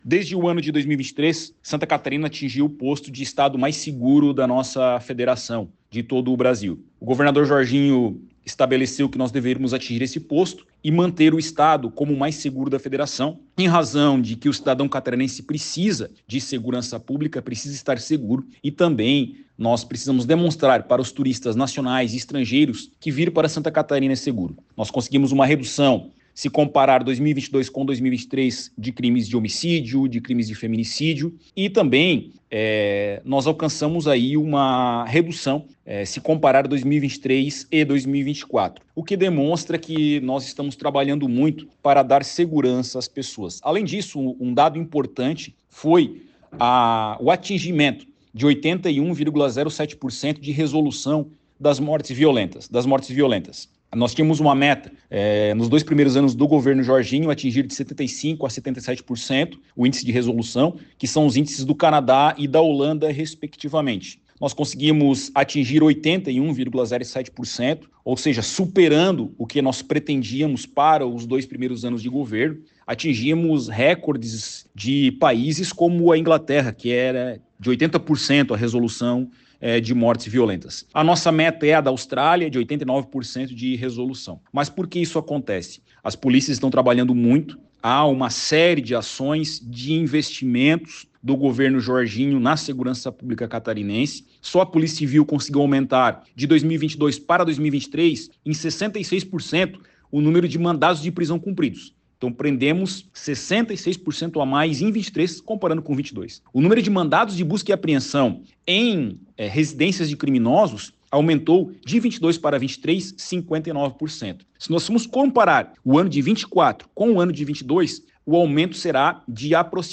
Para o delegado-geral da Polícia Civil de Santa Catarina, Ulisses Gabriel, o trabalho integrado das polícias e as ações e investimentos do governo Jorginho Mello na segurança pública catarinense contribuem para esses resultados:
SECOM-Sonora-Delegado-Geral-PCSC-Reducao-Indices-Criminais.mp3